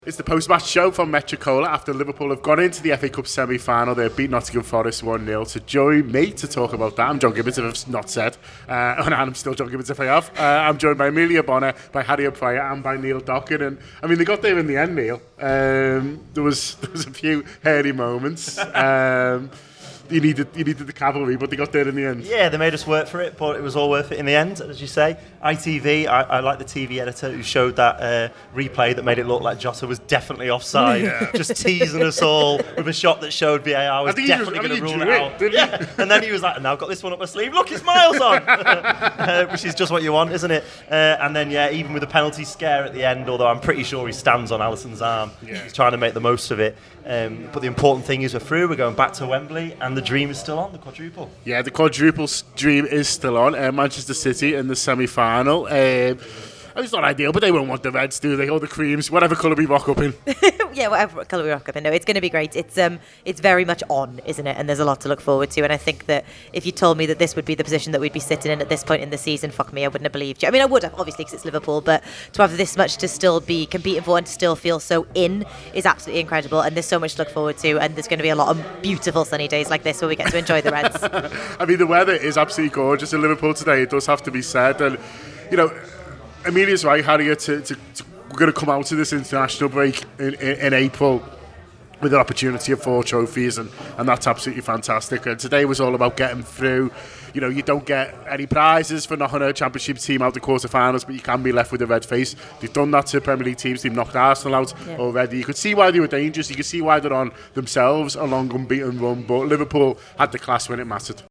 Below is a clip from the show – subscribe for more Nottingham Forest v Liverpool reaction…